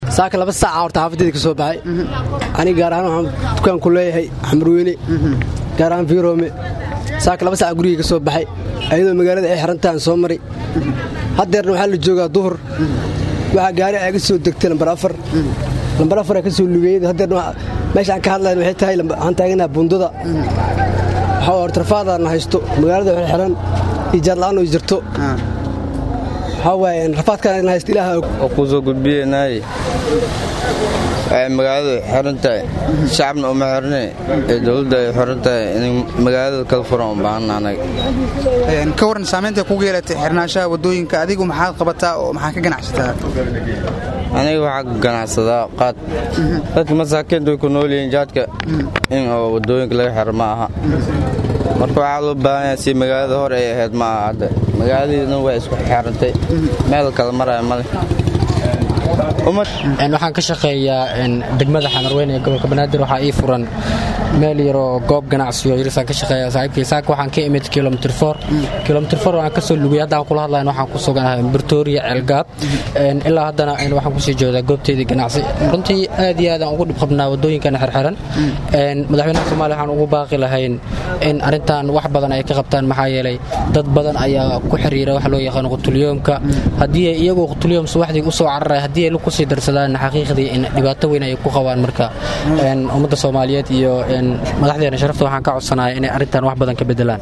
Muqdisho(INO)Qaar ka mid ah Shacabka kunool magaalada Muqdisho ayaa ka hadlay sameynta ay ku yeelatay xirnaanshaha wadooyinka Muqdisho .
Codka-Dadweynaha.mp3